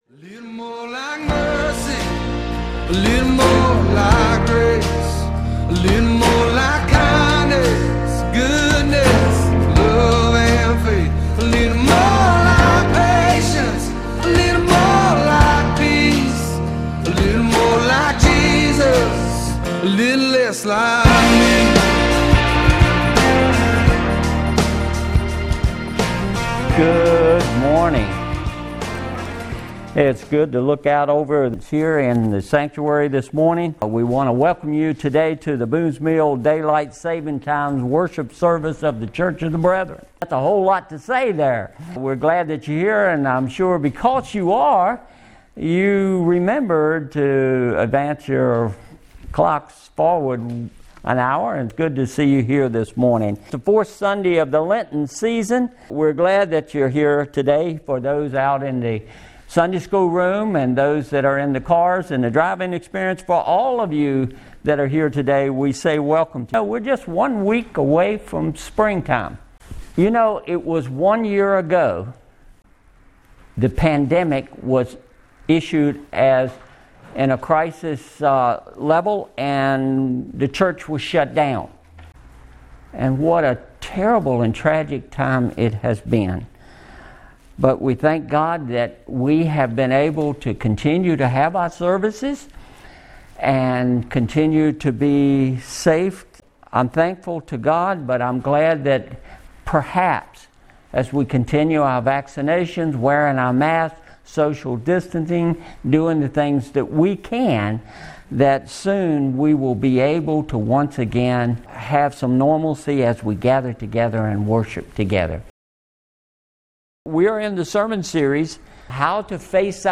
Mar 14, 2021 How to Manage Your Mouth MP3 Notes Discussion Sermons in this Series Sermon Series How to Face Our Future!